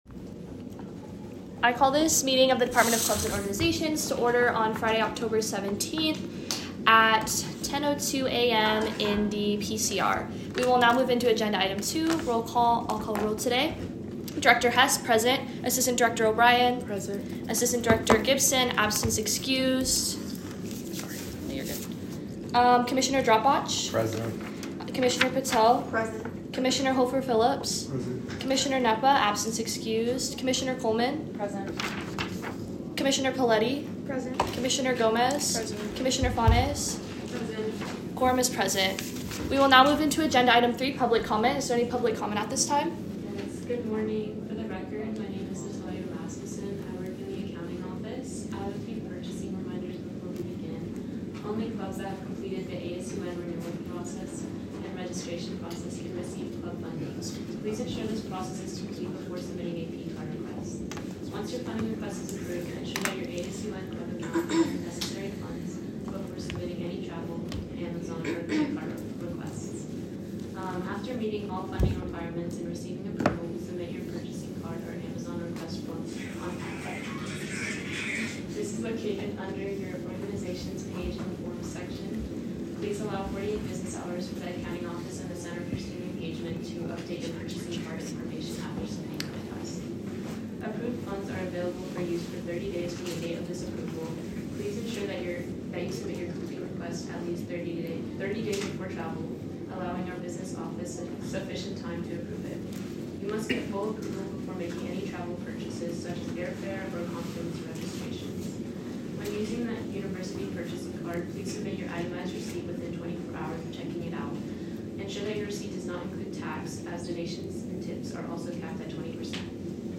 Location : Frankie Sue Del Papa Presidents Conference Room, JCSU 332